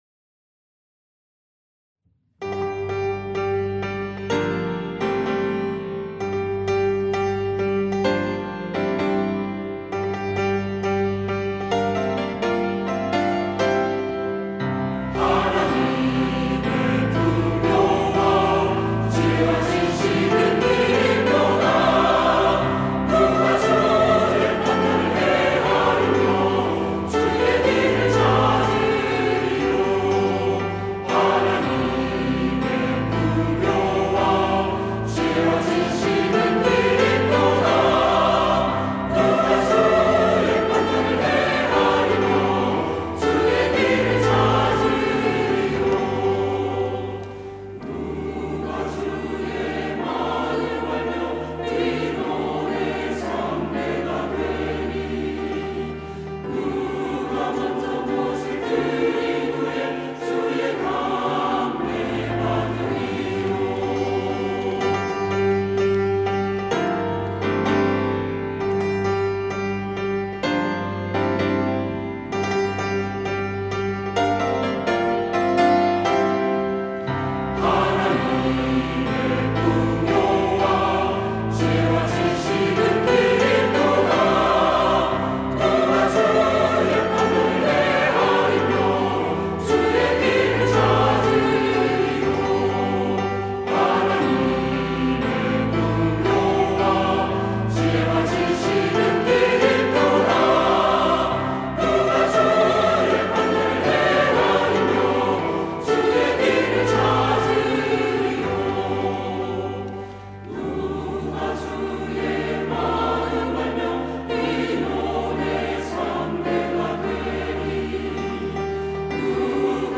시온(주일1부) - 주님께 영광을 영원히
찬양대